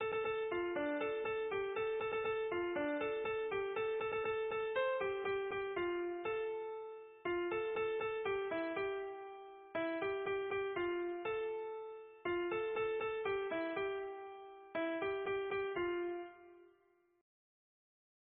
Fonction d'après l'informateur gestuel : à marcher ; Usage d'après l'analyste circonstance : fiançaille, noce
Genre énumérative